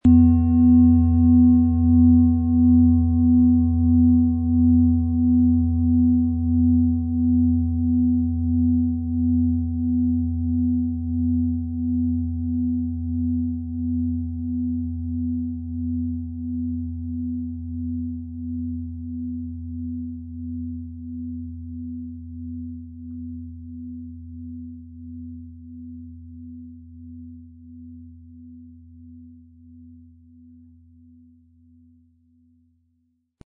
Ø 29,3 cmPlanetenschale® Nach oben öffnend & Liebevoll sein mit Platonisches Jahr & Delfin-Ton inkl. Klöppel
Ein unpersönlicher Ton.
• Mittlerer Ton: Delfin
MaterialBronze